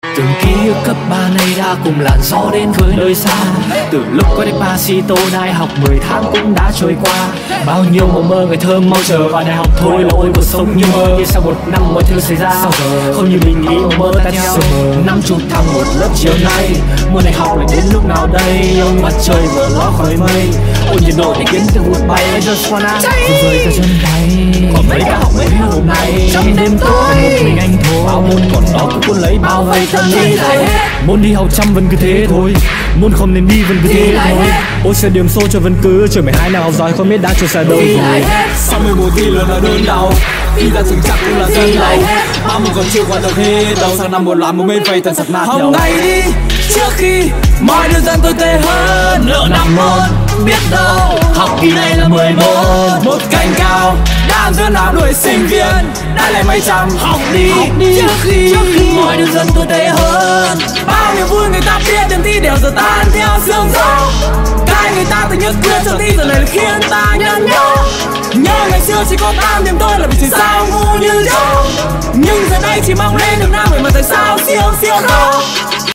Thể loại nhạc chuông: Nhạc hài hước